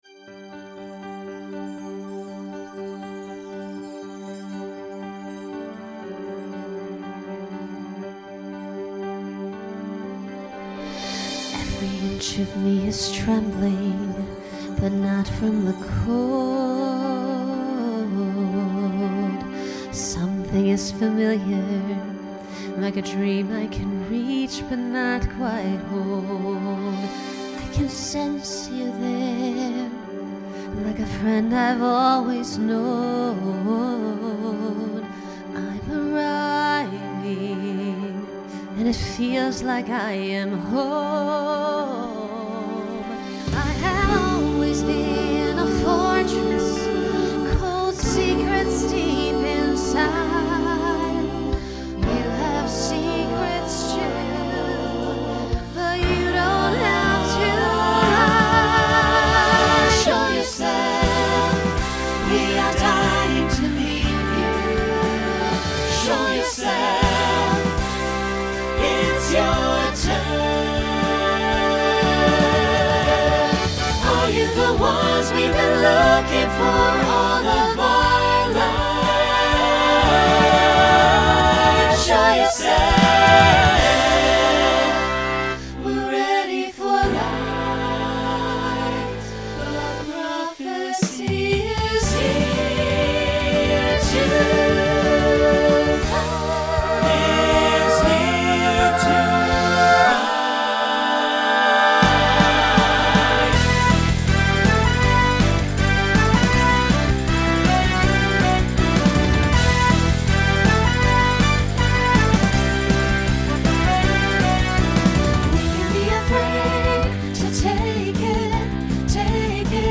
Genre Broadway/Film , Rock Instrumental combo
Voicing SATB